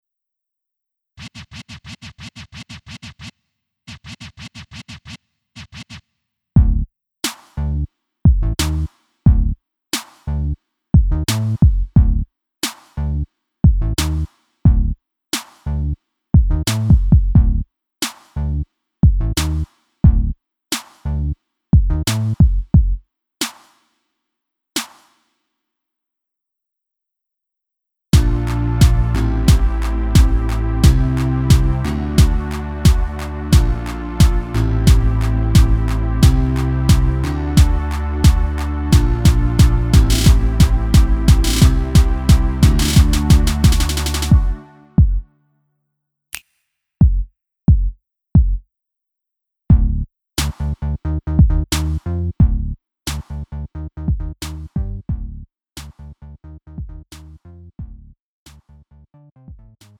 음정 -1키 3:33
장르 가요 구분 Lite MR
Lite MR은 저렴한 가격에 간단한 연습이나 취미용으로 활용할 수 있는 가벼운 반주입니다.